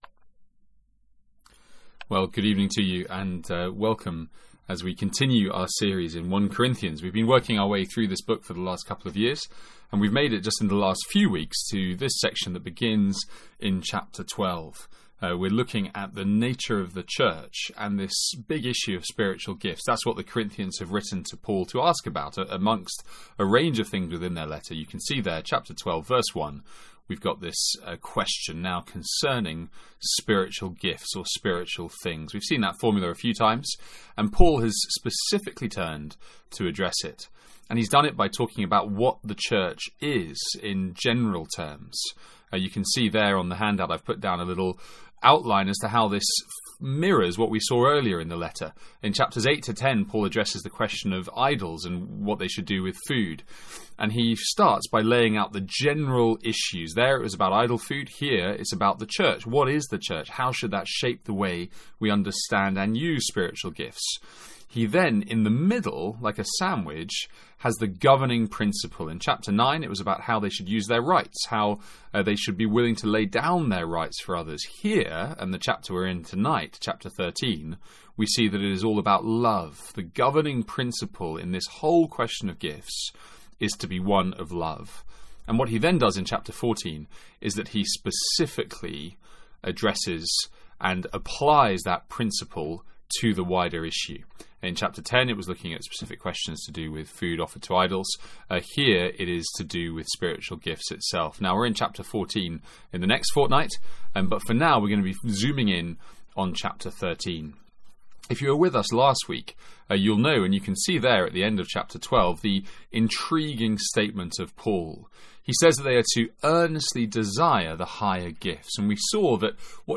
From our evening service on 1 Corinthians.
NB. This was re-recorded after the initial event.